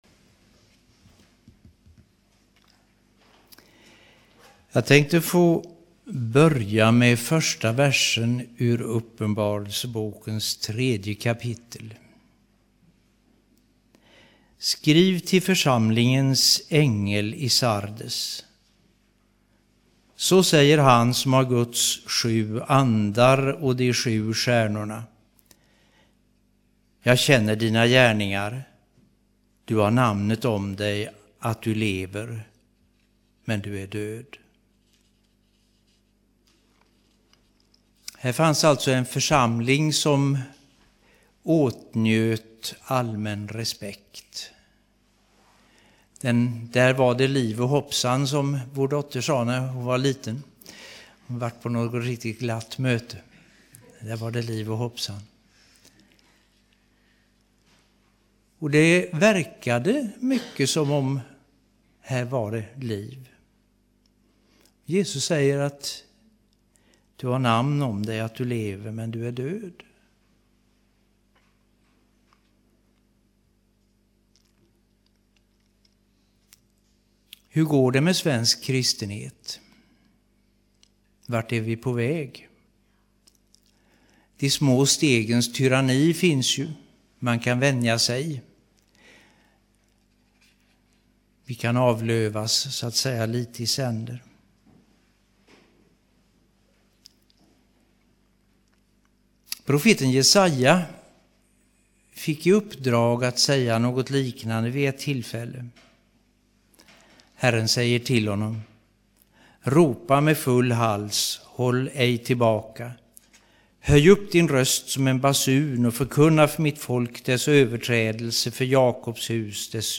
2007-12-09 Predikan av